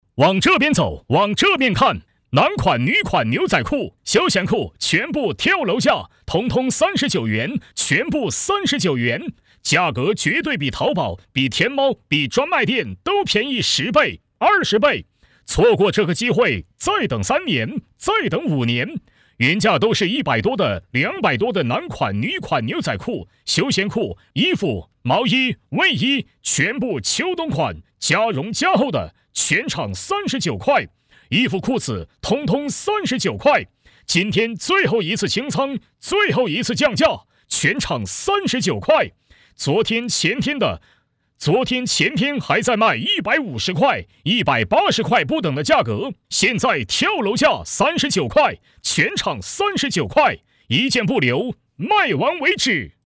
淘声配音网，专题，宣传片配音，专业网络配音平台